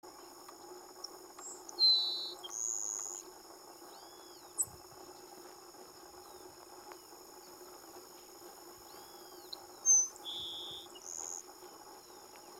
Tico-tico-do-campo (Ammodramus humeralis)
Nome em Inglês: Grassland Sparrow
Fase da vida: Adulto
Localidade ou área protegida: Perdices
Condição: Selvagem
Certeza: Fotografado, Gravado Vocal
Cachilo-ceja-amarilla-Perdices.mp3